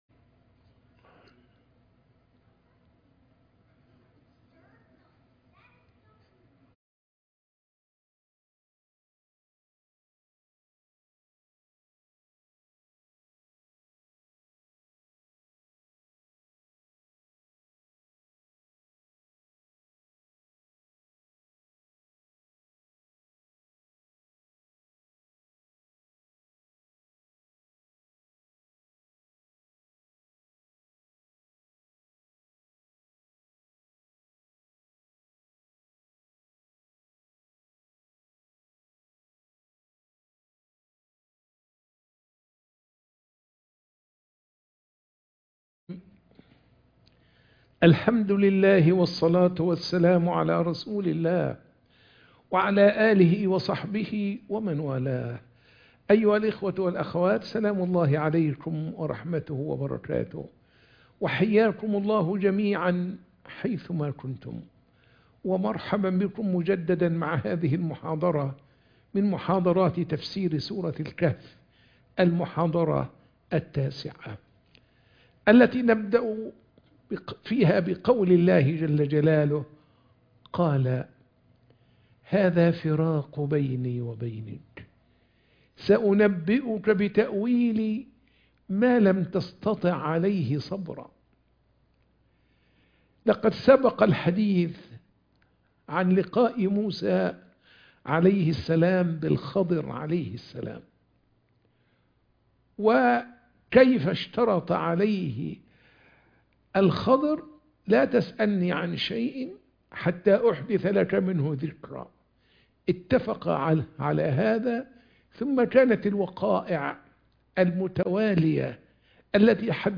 سورة الكهف - المحاضرة 9